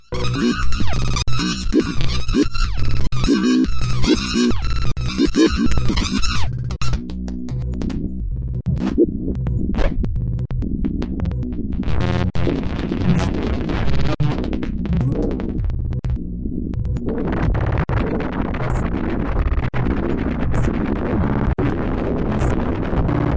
描述：抽象毛刺效应
Tag: 科幻 奇怪 音响设计 效果 设计 毛刺 随机 摘要